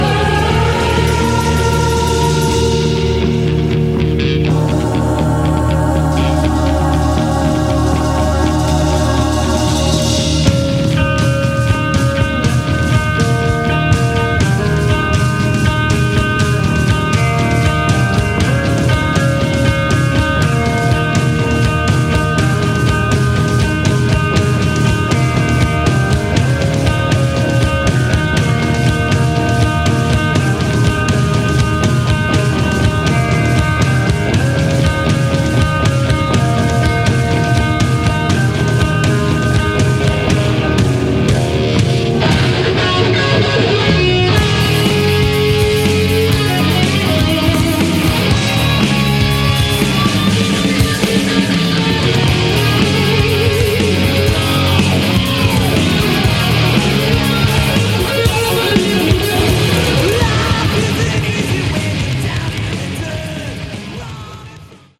Category: Hard Rock
lead guitar, backing vocals
bass
drums, percussion